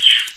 PixelPerfectionCE/assets/minecraft/sounds/mob/bat/hurt4.ogg at ca8d4aeecf25d6a4cc299228cb4a1ef6ff41196e